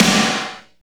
51.10 SNR.wav